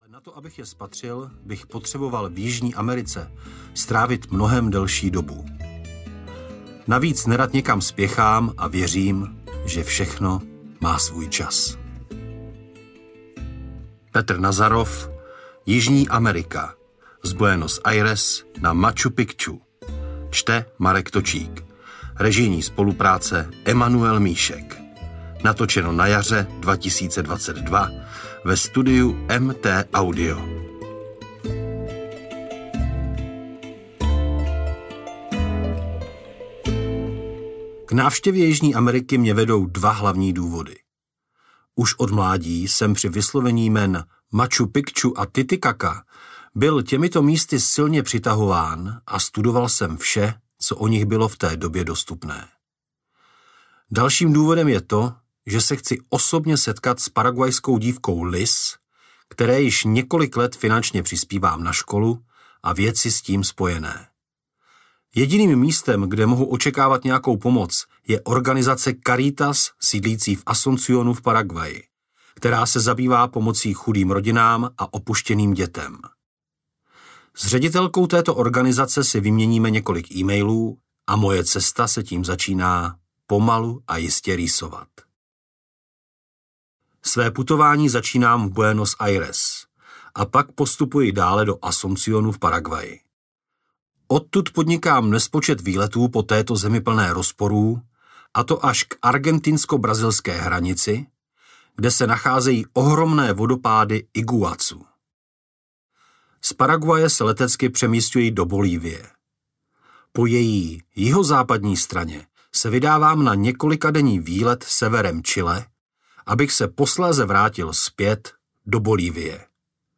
Jižní Amerika audiokniha
Ukázka z knihy